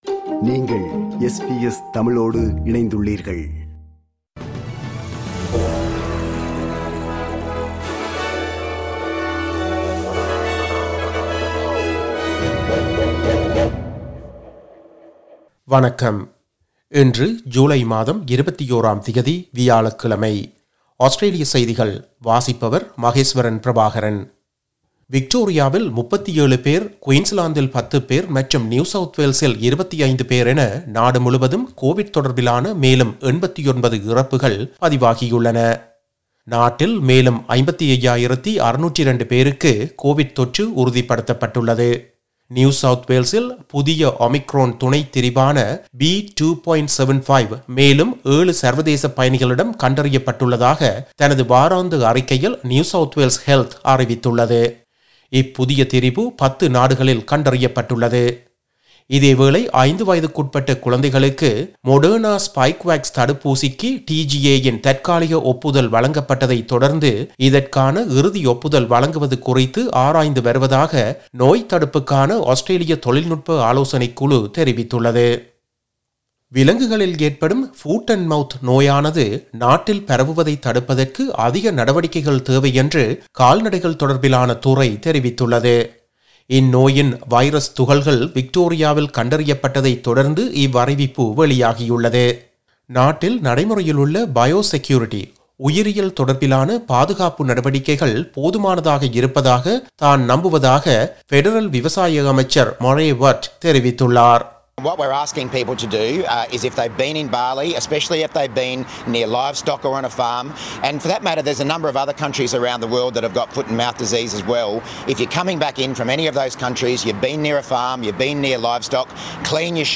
Australian news bulletin for Thursday 21 July 2022.